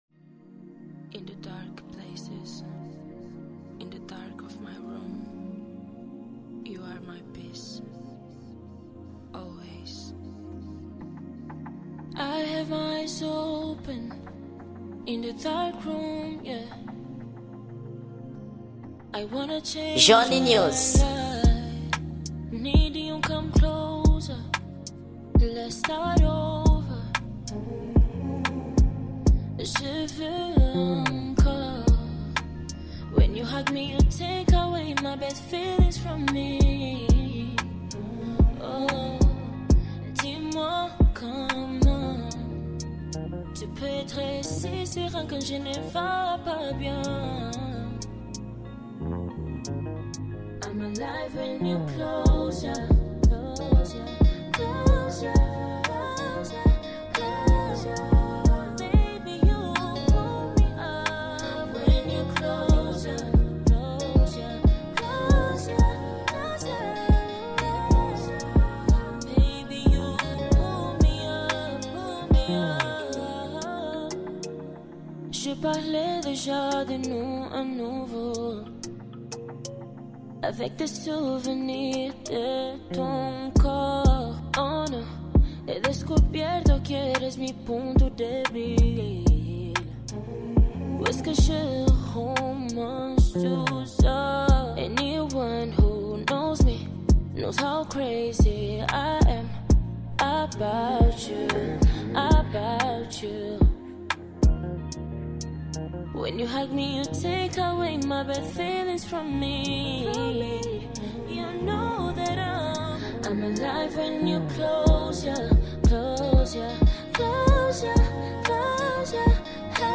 Gênero: Acústico